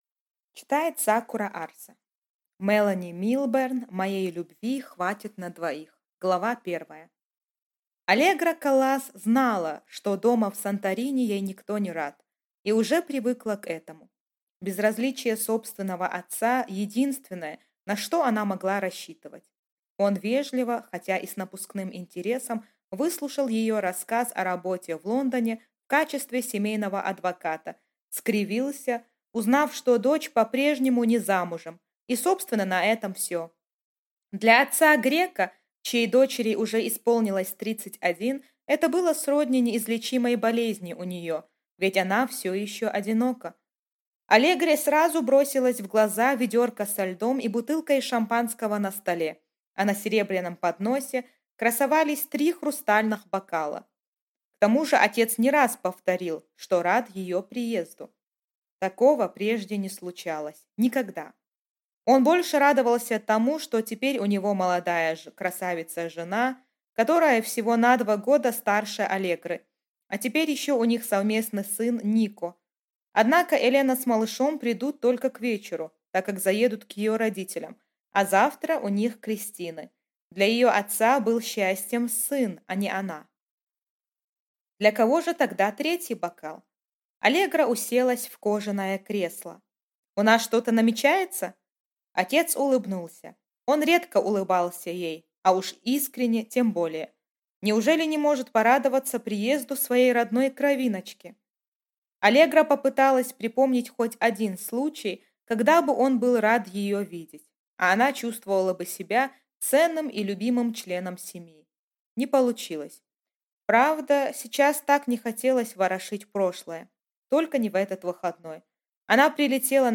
Аудиокнига Моей любви хватит на двоих | Библиотека аудиокниг
Прослушать и бесплатно скачать фрагмент аудиокниги